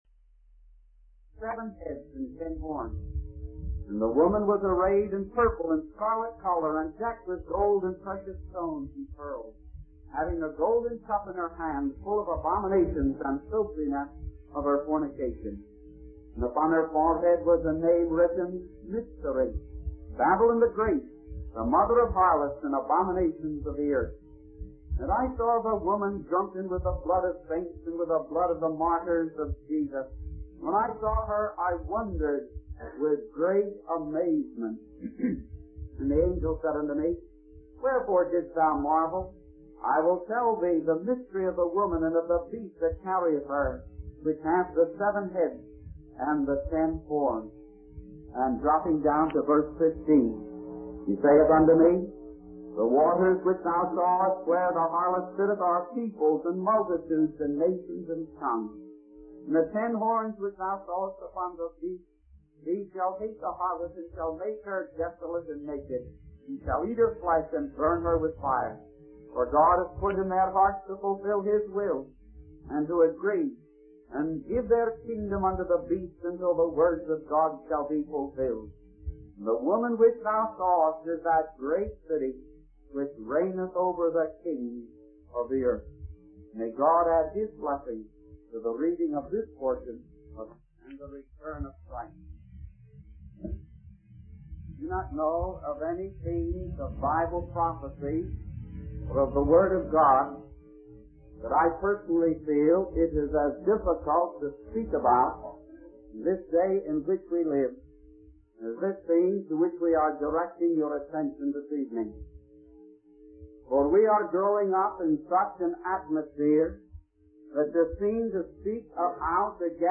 In this sermon, the speaker focuses on a specific verse from the Bible, Revelation 17:3, which describes a woman riding a beast.